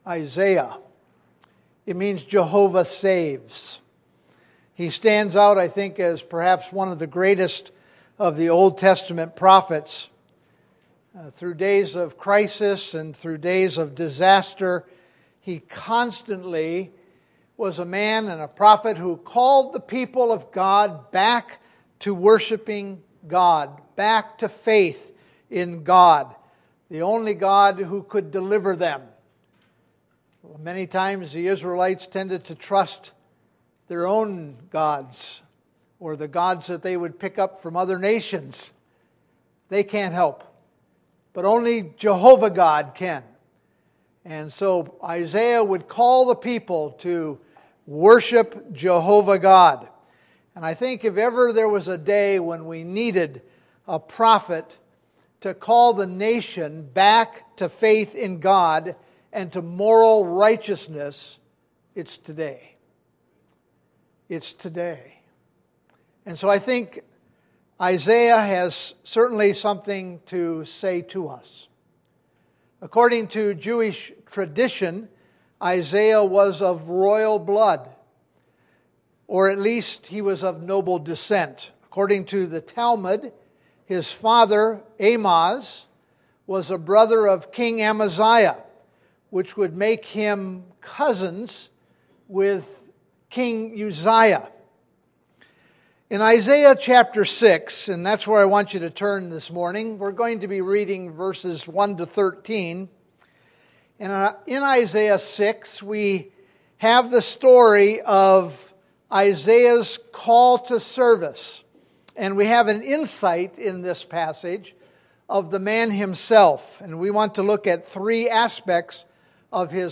Sermons - New Leaves
Random Archived Sermon Selection